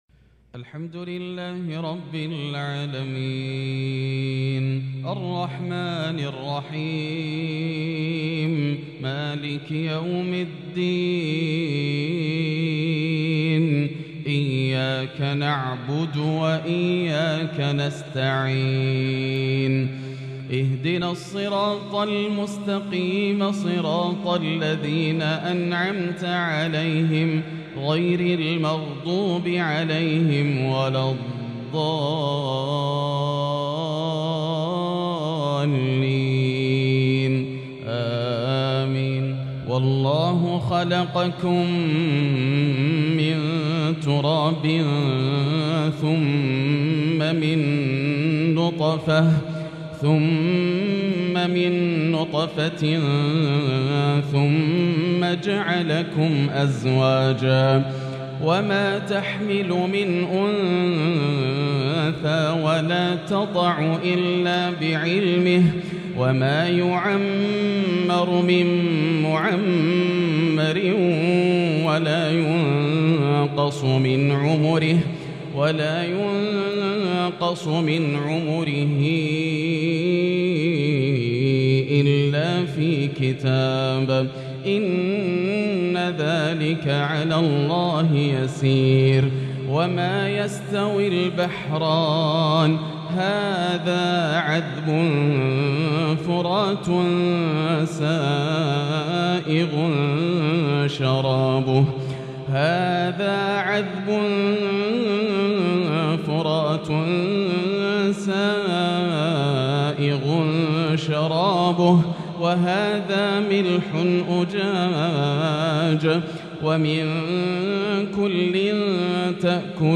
عشاء الثلاثاء 7-7-1443هـ من سورة فاطر | Isha prayer from Surat Fatir 8-2-2022 > 1443 🕋 > الفروض - تلاوات الحرمين